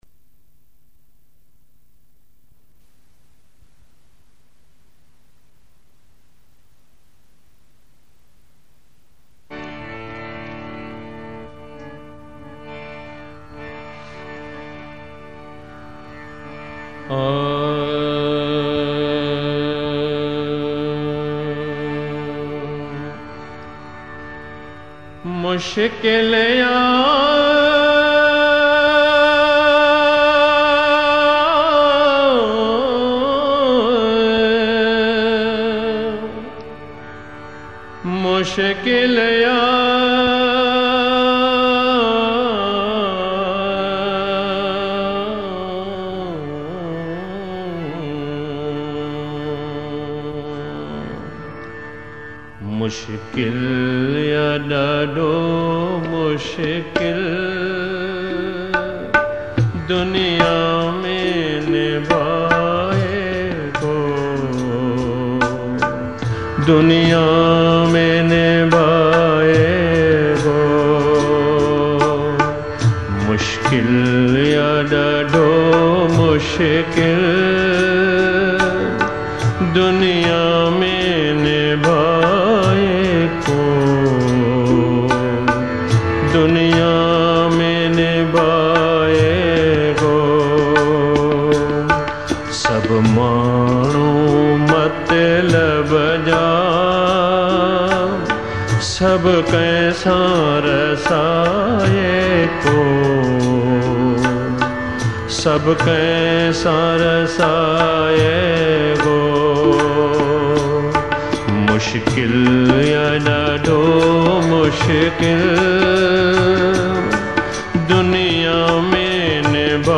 Classical Spiritual Sindhi Songs